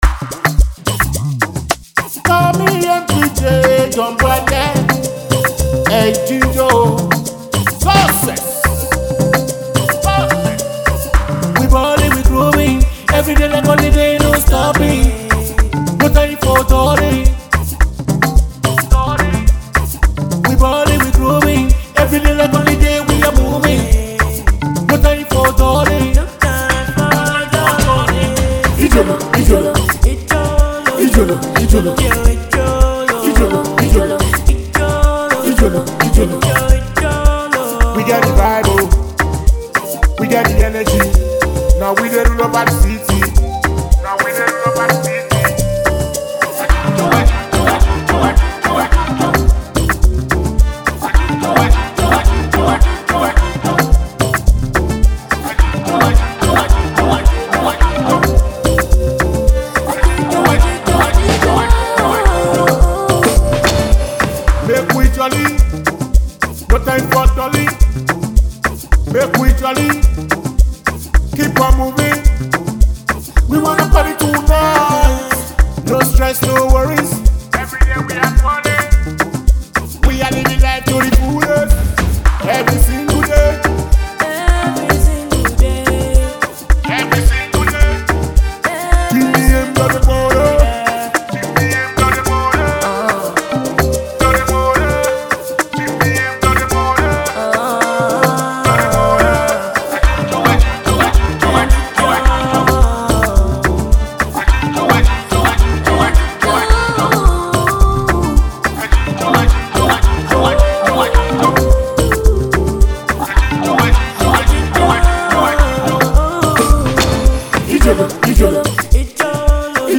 high-energy banger
Blending Afrobeats, Amapiano, and street vibes